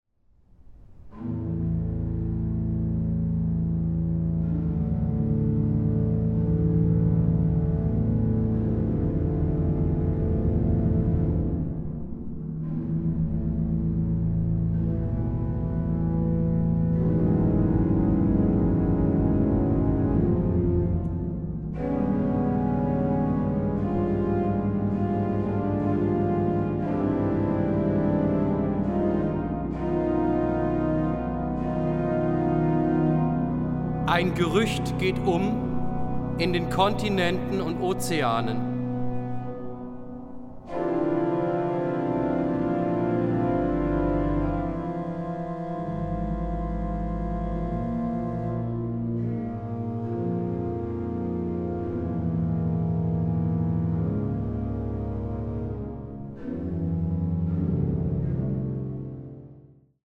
Orgel
Aufnahme: Het Orgelpark, Amsterdam, 2023